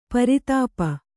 ♪ pari tāpa